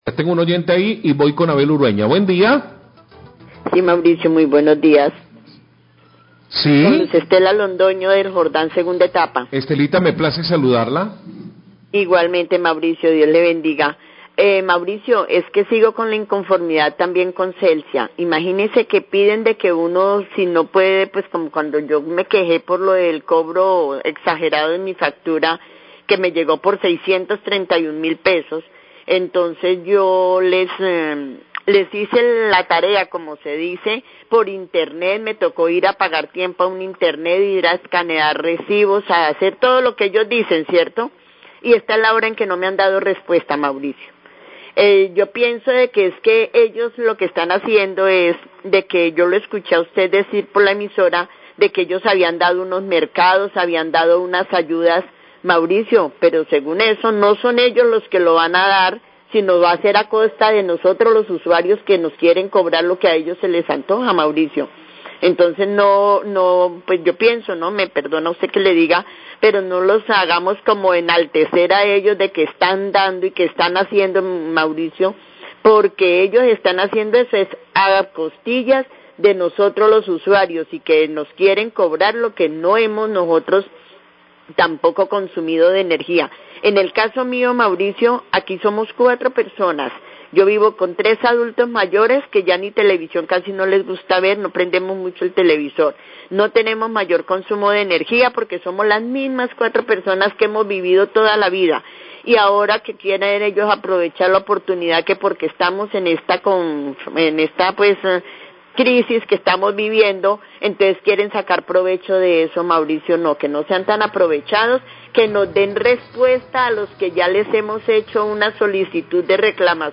Queja oyente por falta de respuesta de Celsia ante reclamo por alzas energía
Radio